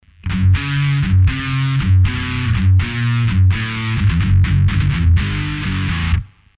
Тут позиция такая - с приведенным в этой же статье спикерсимулятором в линию все зазвучит гораздо приятнее, чем в дешевый комбо (что подтверждают звуки, записанные именно в линию, а не с микрофона).
(76kB) - улучшенный преамп, средний драйв, bridge+neck
new_medium_both.mp3